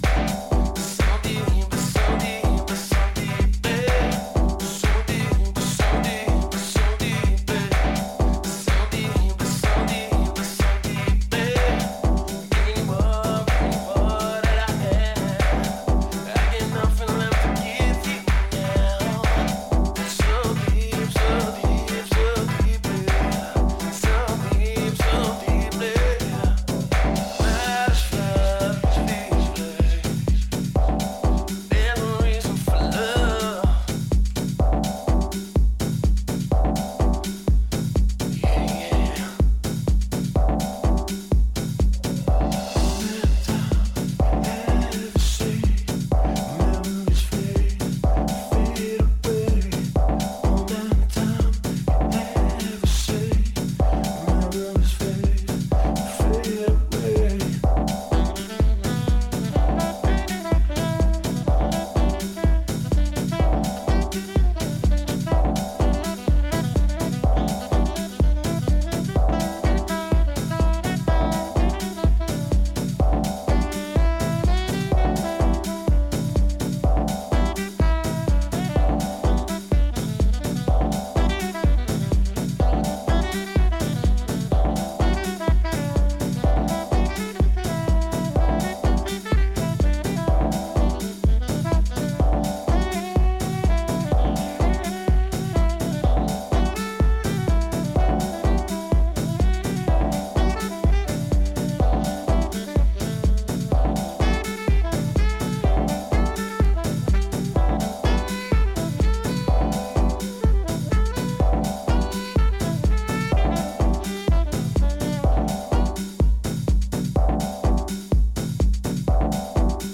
こちらもディープでソウルフルなフィーリングが光る秀逸な仕上がりとなっています